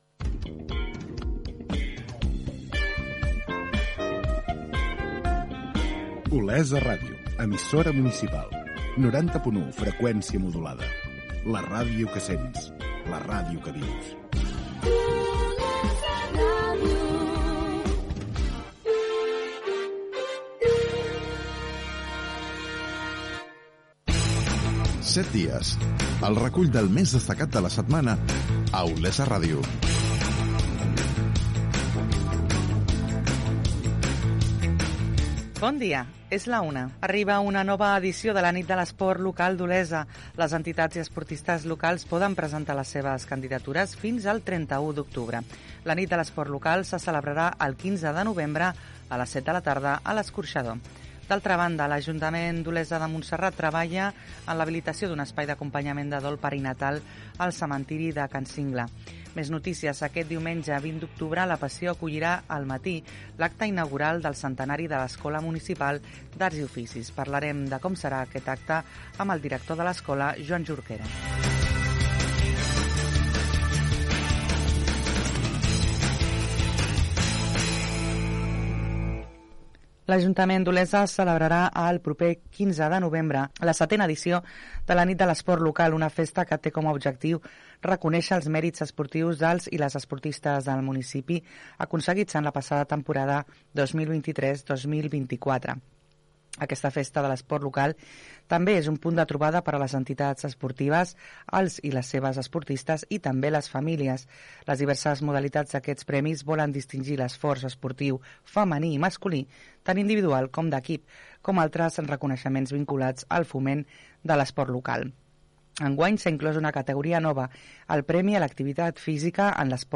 Identificació de l'emissora, careta del programa, hora, sumari, la nit de l'esport local d'Olesa Gènere radiofònic Informatiu